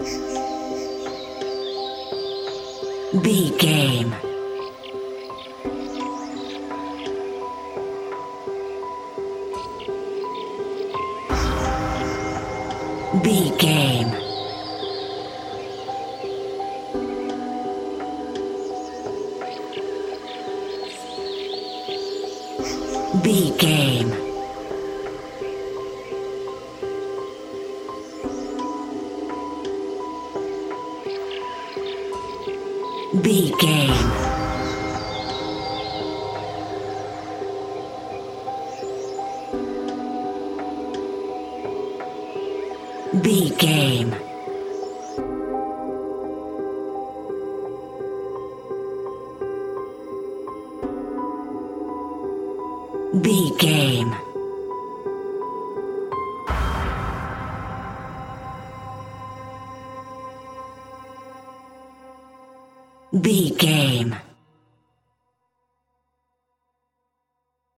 Aeolian/Minor
Slow
ominous
dark
haunting
eerie
piano
synthesiser
horror music
Horror Pads